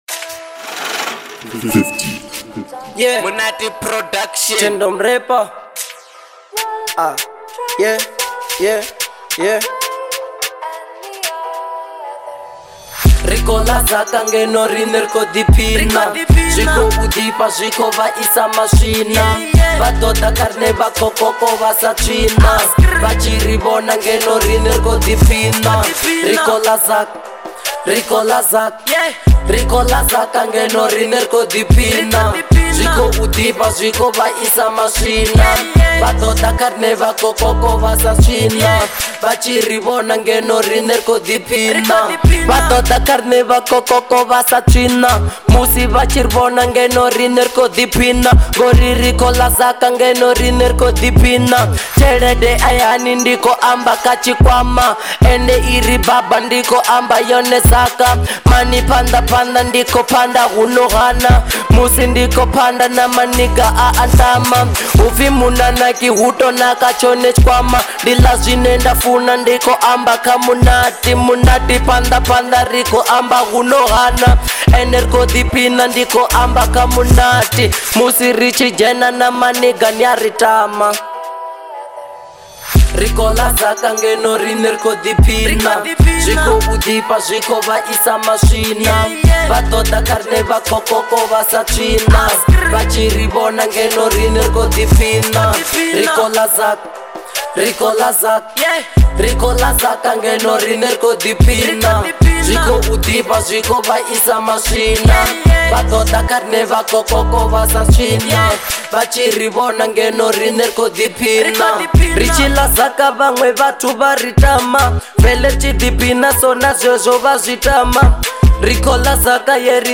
03:20 Genre : Venrap Size